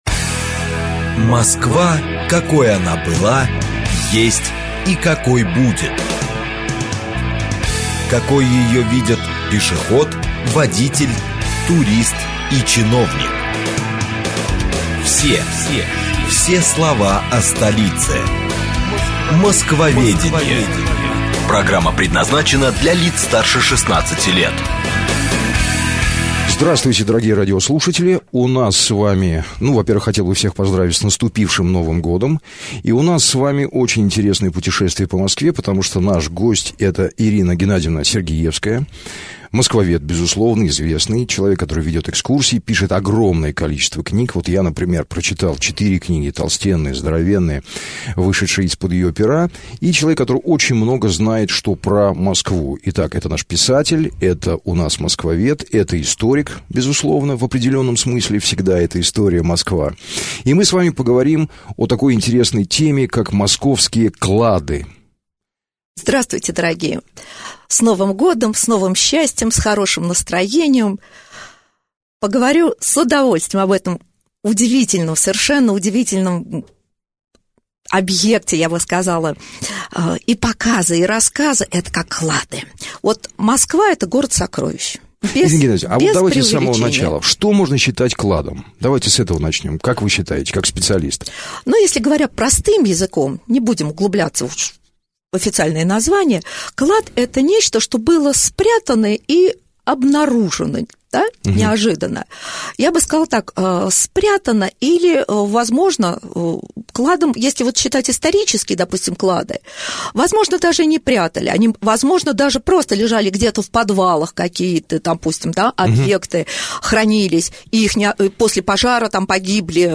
Аудиокнига Московские клады | Библиотека аудиокниг
Прослушать и бесплатно скачать фрагмент аудиокниги